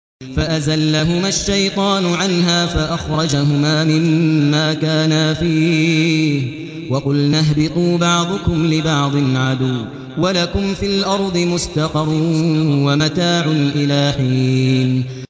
Automatic Speech Recognition
quran-data / data /audio /Qari /Maher_AlMuaiqly /002036_Maher_AlMuaiqly_64kbps.wav